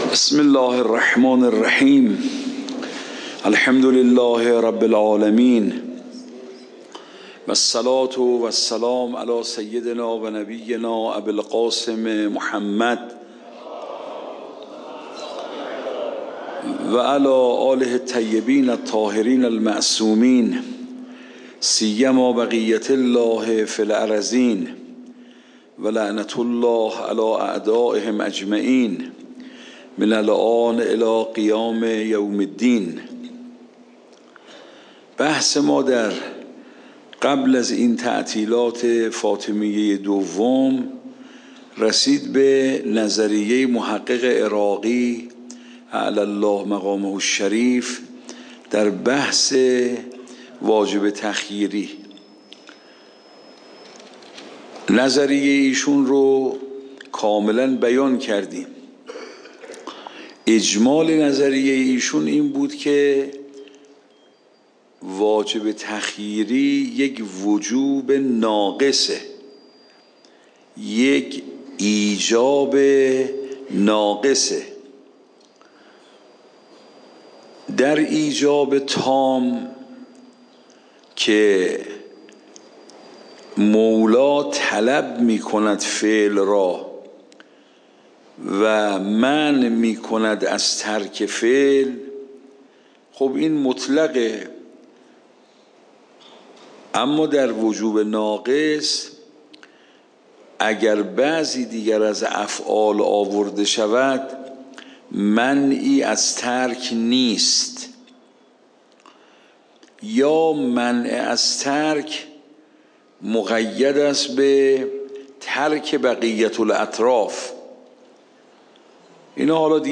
درس بعد واجب تعیینی و تخییری درس قبل واجب تعیینی و تخییری درس بعد درس قبل موضوع: واجب تعیینی و تخییری اصول فقه خارج اصول (دوره دوم) اوامر واجب تعیینی و تخییری تاریخ جلسه : ۱۴۰۴/۹/۸ شماره جلسه : ۳۷ PDF درس صوت درس ۰ ۷۳